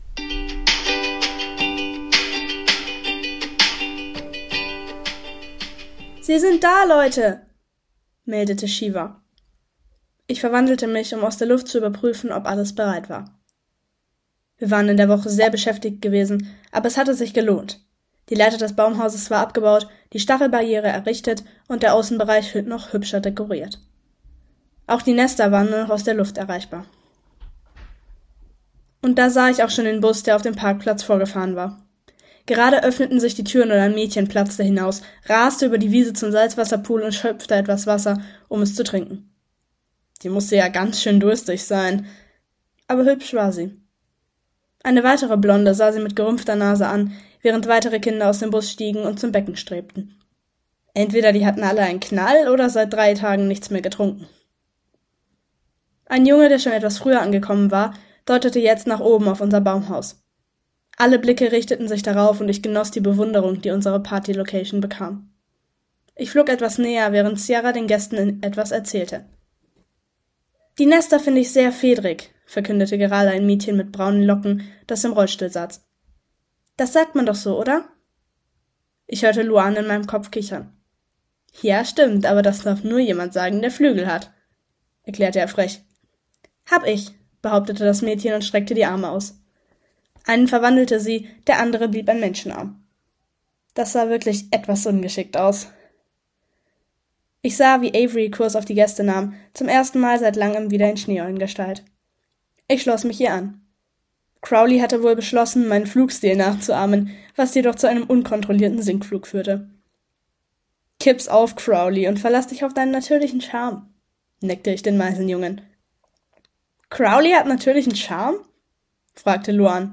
Beschreibung vor 1 Jahr In dieser Folge Kapitel 9/45 Und sorry für die ganzen Versprecher und Hintergrundgeräusche, ich habe heute aber keine Zeit, es nochmal besser aufzunehmen🥲 - Infos Der Plott entspricht der aus Seawalkers Band sechs, Im Visier der Python.